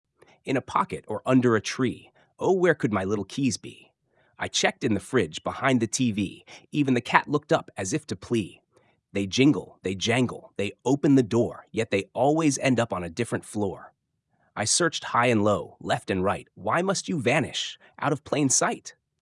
ChatGPT предлагает выбор из пяти различных голосов — «можжевельник», «бухта», «небо», «бриз» и «уголь». В их записи участвовали профессиональные актеры.
Стихотворение ChatGPT. Данные: OpenAI.
poem-ember.mp3